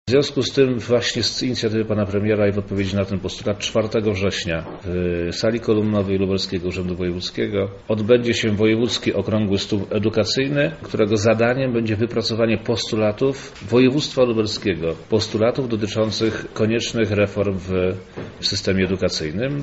O szczegółach nadchodzącej debaty mówi Wojewoda Lubelski Przemysław Czarnek: